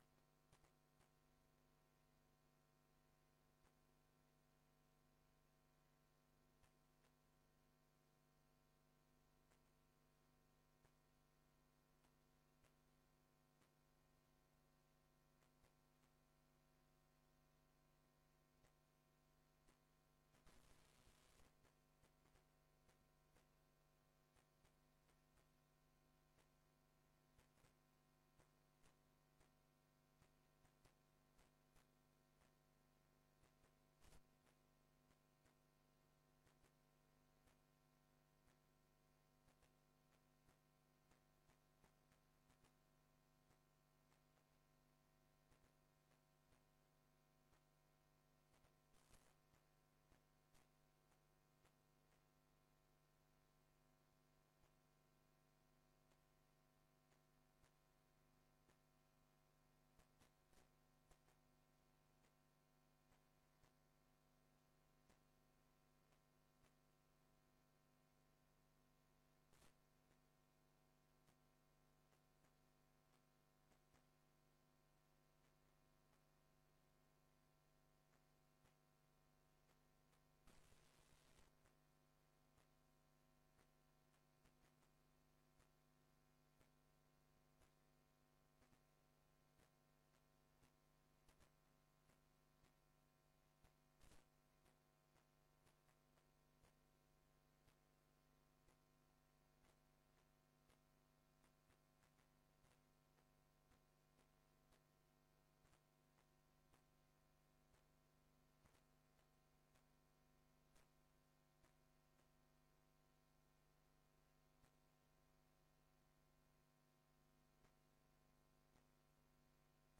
Download de volledige audio van deze vergadering